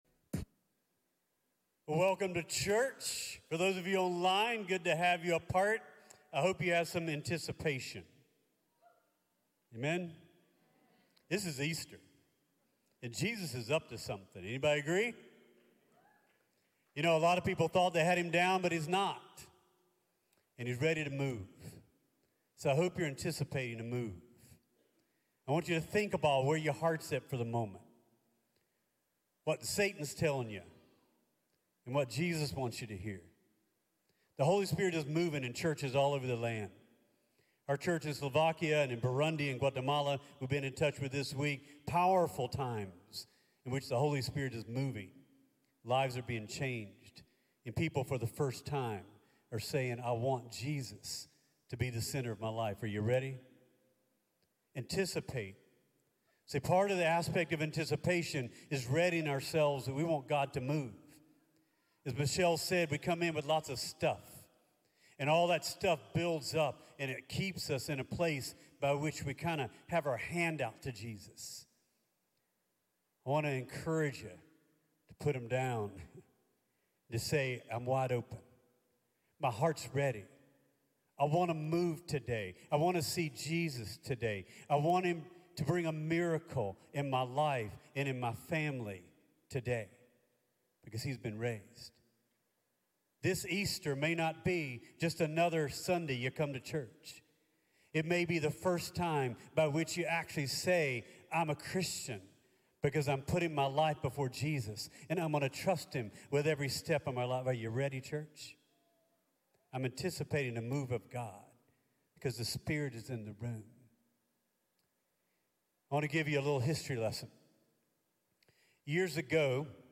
CTK-Clipped-Sermon.mp3